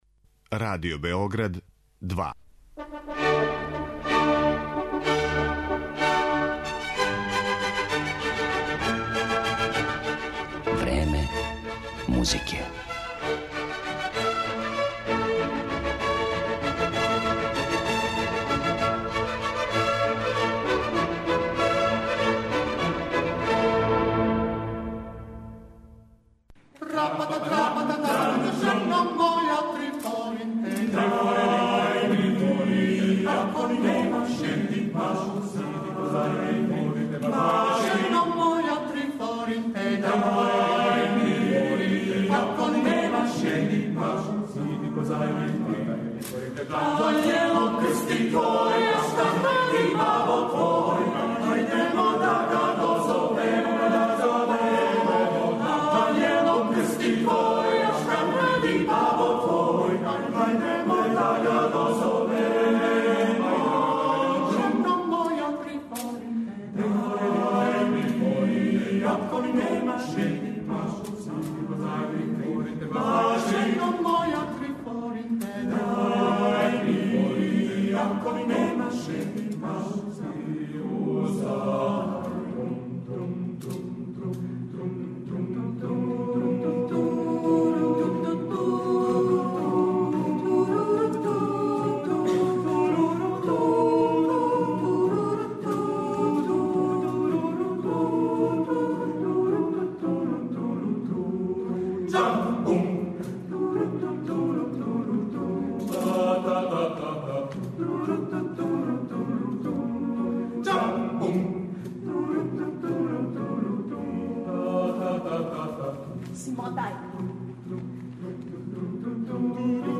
Емисија ће бити реализована из Неготина, у коме се одржавају 49. Мокрањчеви дани.